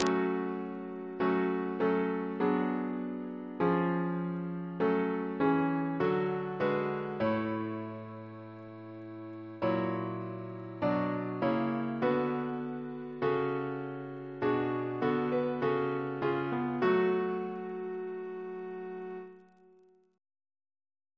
Double chant in F♯ minor Composer: Sir Joseph Barnby (1838-1896), Precentor of Eton, Principal of the Guildhall School of Music Reference psalters: ACB: 185; ACP: 163; CWP: 6; RSCM: 17